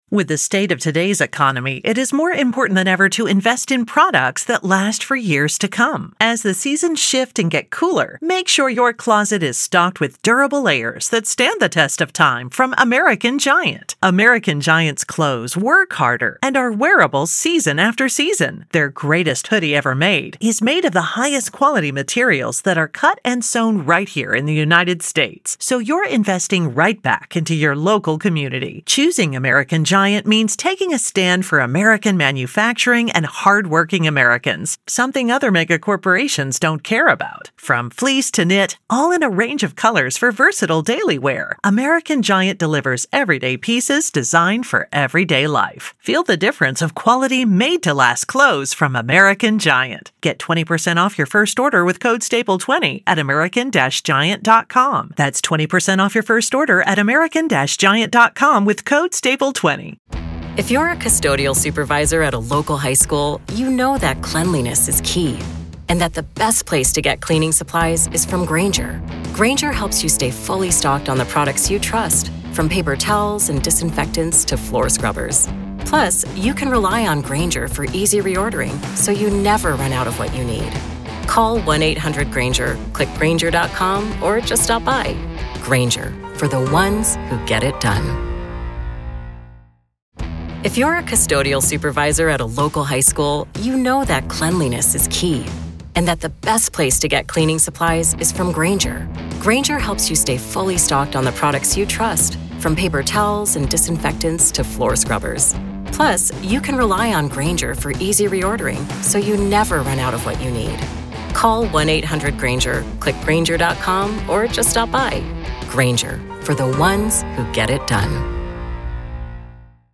In this explosive interview